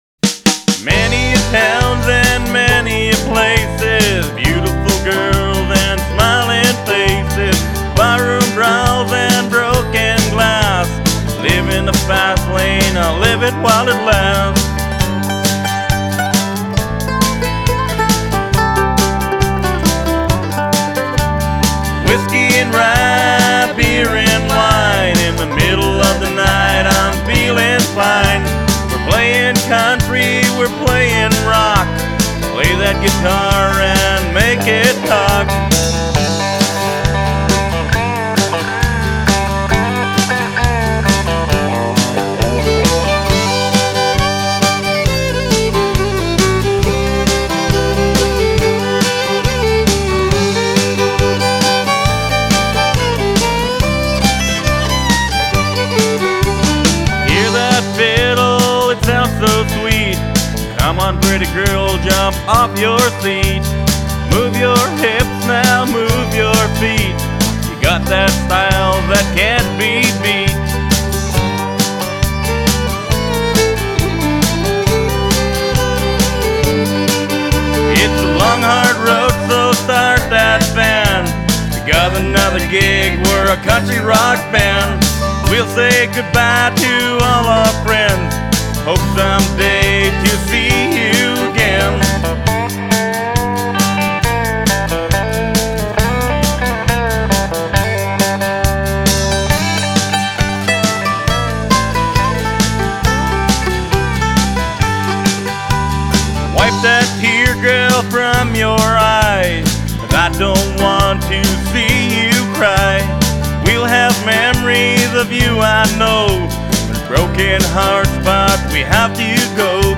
At first, it sounds okay and then it hits you...